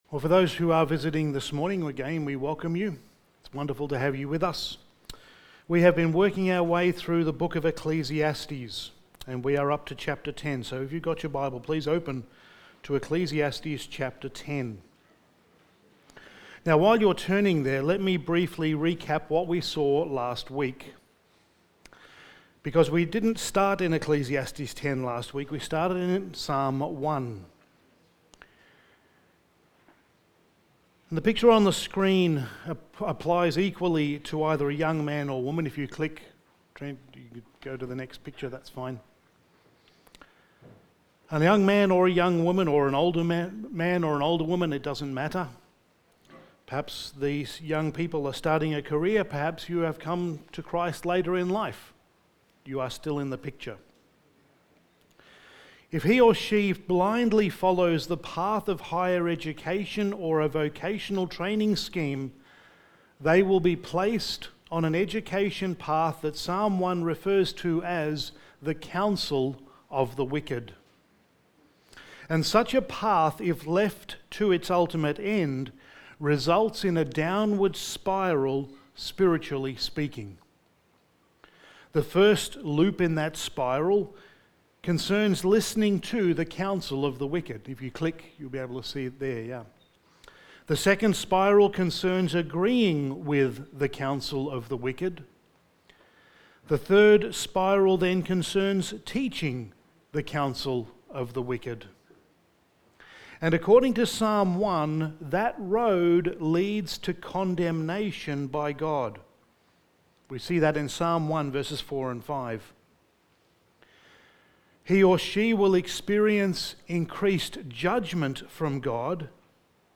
Studies in the Book of Ecclesiastes Sermon 21: A Word to the Wise
Service Type: Sunday Morning